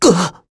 Lucias-Vox_Damage_01_kr.wav